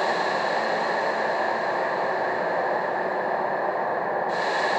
Index of /musicradar/sparse-soundscape-samples/Creep Vox Loops
SS_CreepVoxLoopB-11.wav